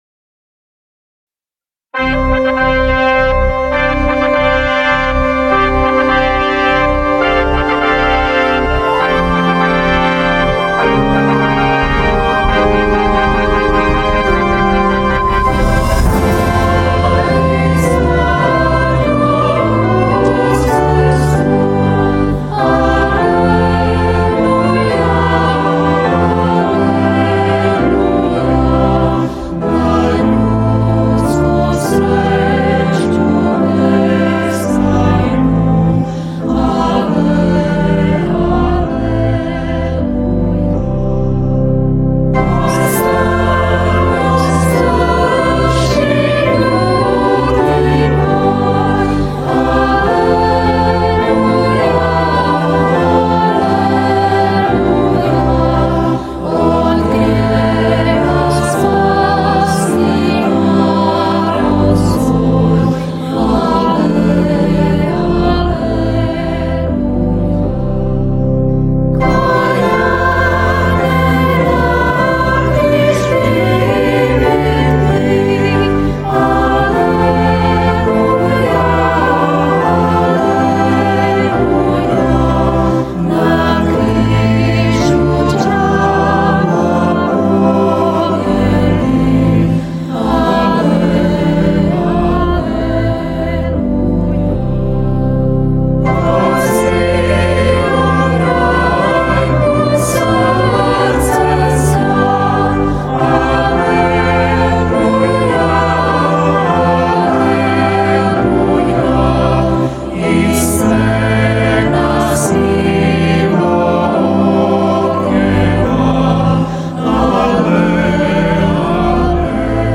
Liturgijske